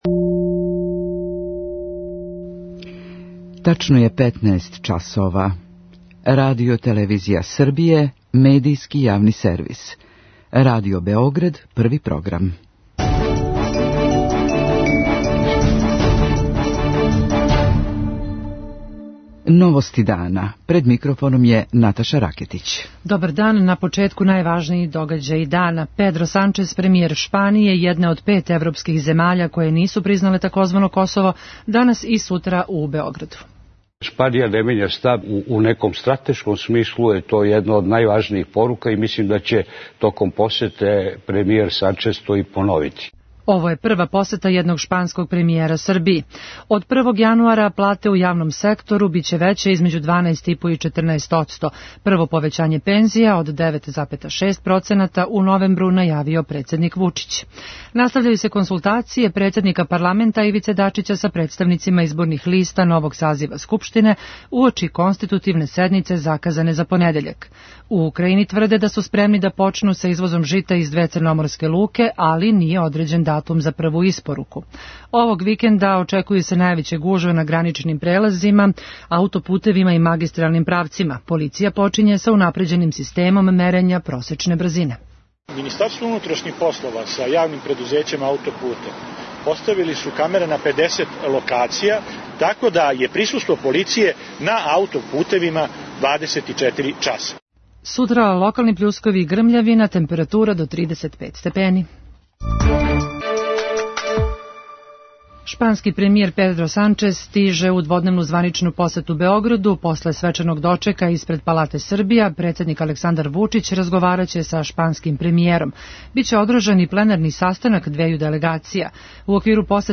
Хумористичка емисија